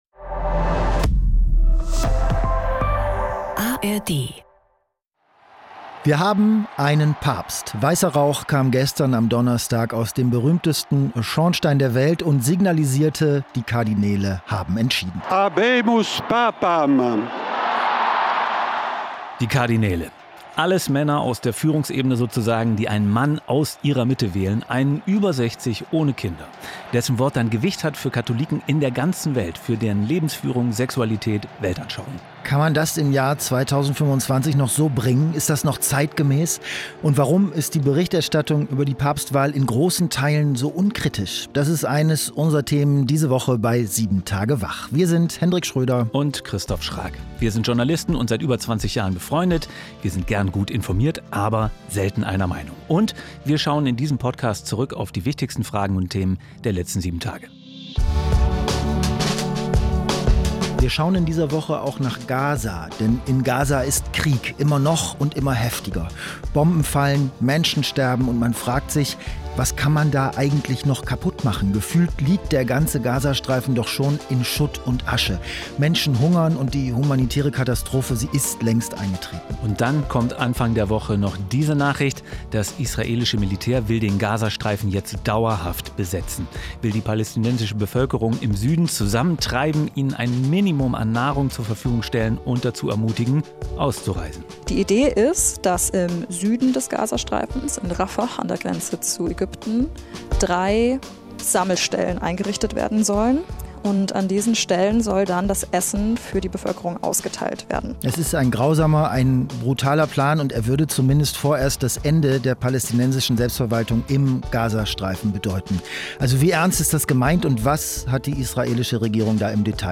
Zwei Freunde, zwei Meinungen, ein News-Podcast